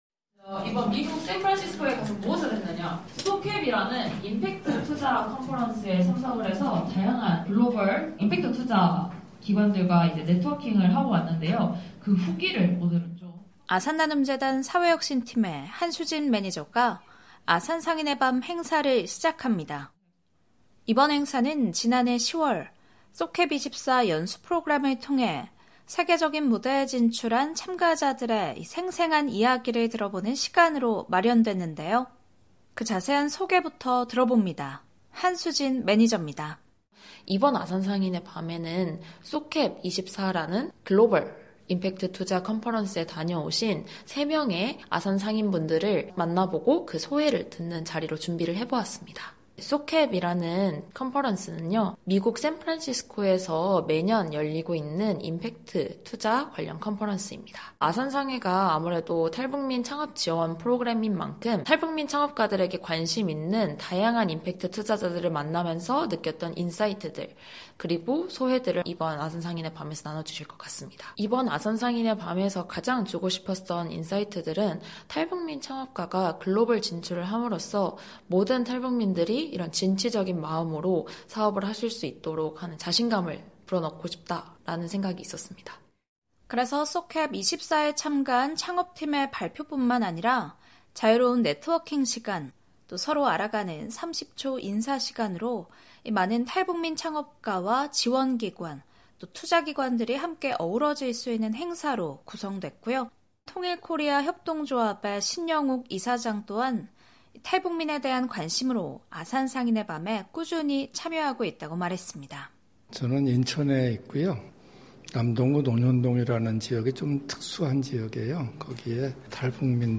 탈북민들의 다양한 삶의 이야기를 전해드리는 ‘탈북민의 세상 보기’, 오늘은 ‘아산상인의 밤’ 현장 소식 전해 드립니다.